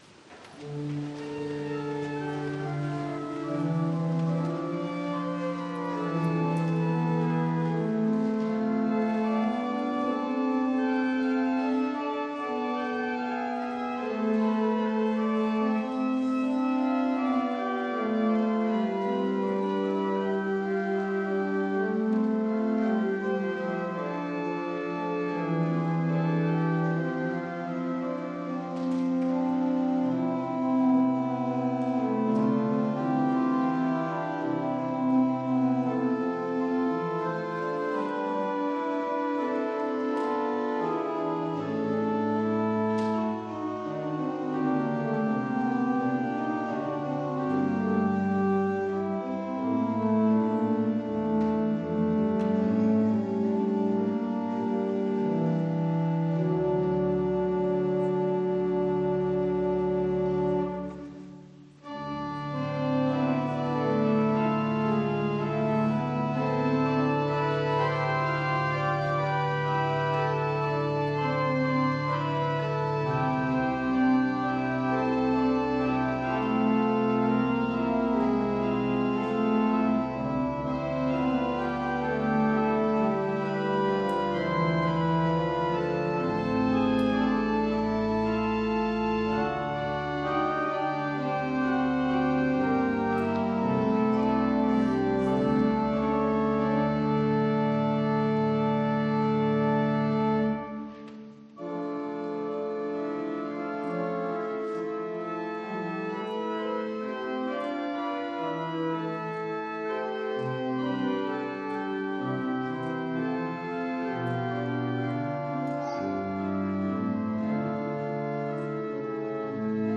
Gottesdienst am 07.03.2021